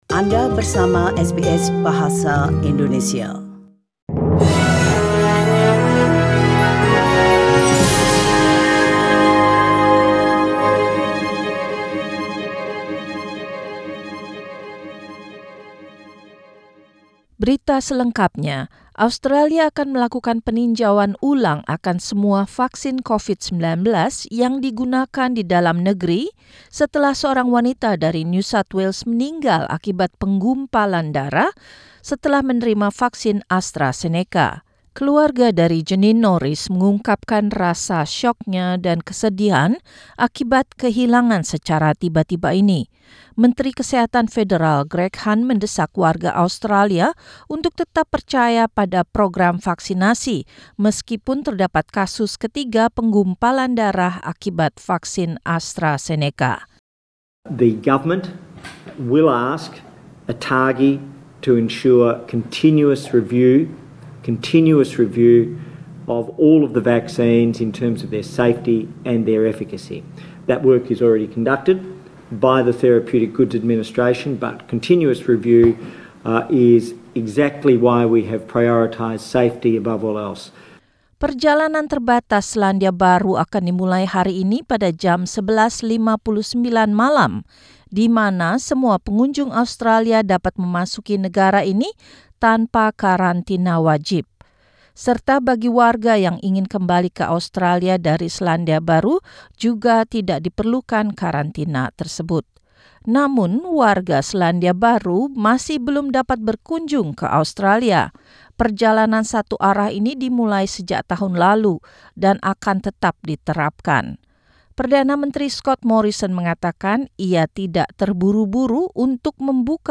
SBS Radio News in Indonesian - Sunday, 18 April 2021